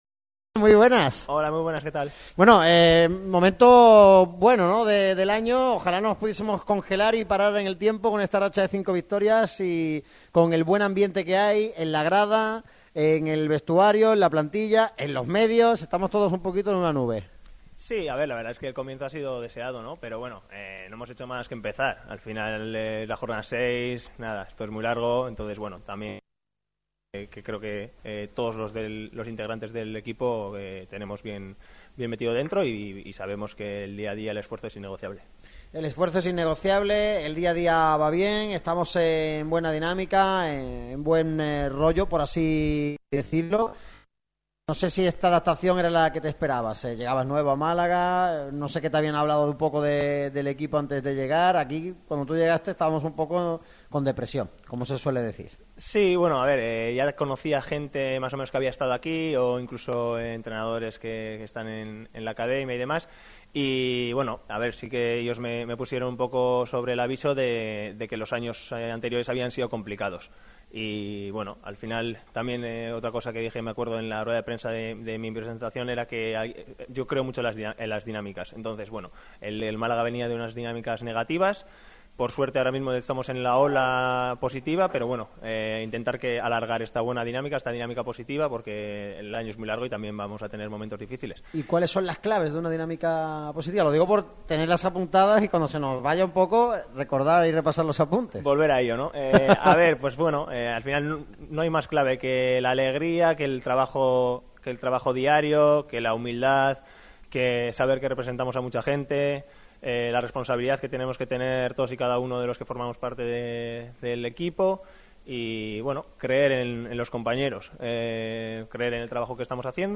El central malaguista ha atendido al micrófono rojo de Radio MARCA Málaga en la mañana de hoy.